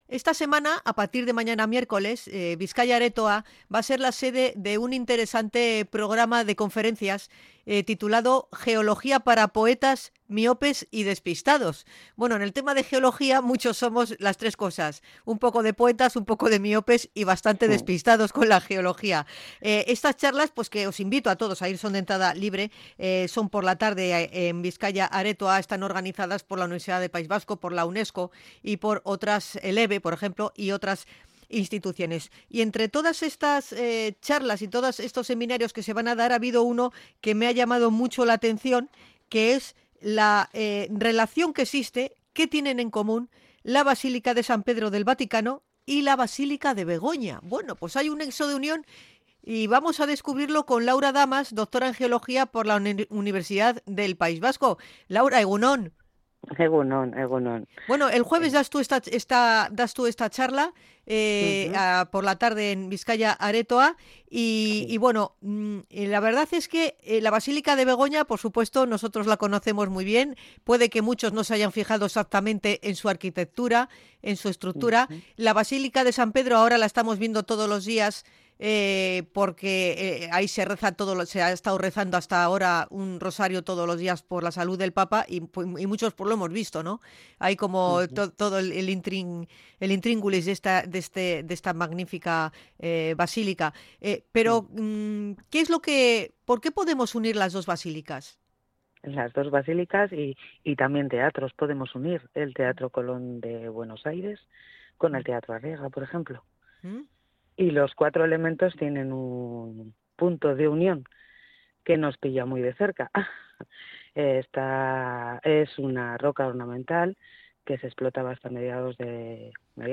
INT.-BASILICAS-DE-SAN-PEDRO-Y-BEGONA.mp3